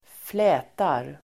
Uttal: [²fl'ä:tar]